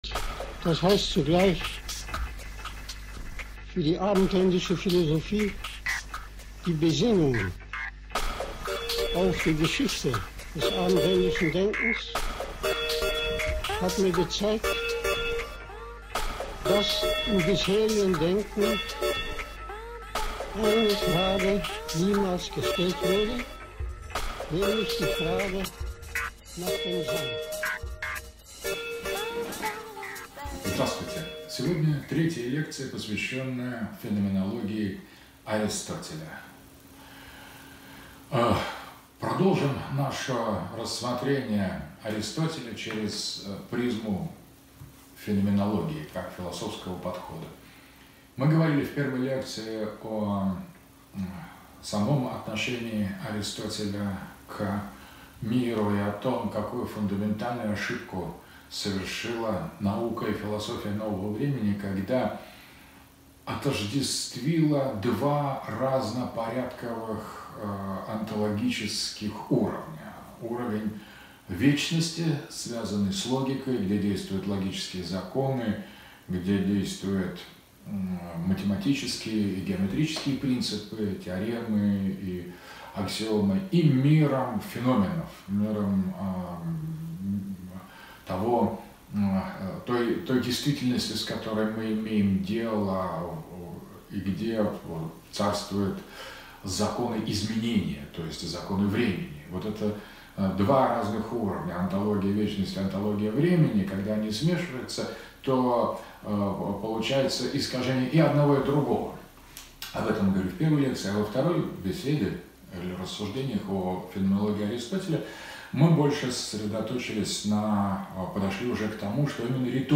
Феноменология Аристотеля. Лекция № 5.